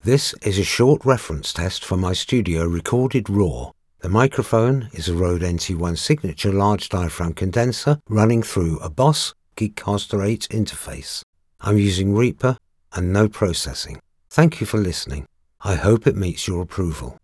My studio and recording equipment are selected and optimised to eliminate reflections and nasty audio frequencies.
Studio Quality Sample
Raw Demo Recording Studio
Words that describe my voice are Neutral British, Clear, Expressive.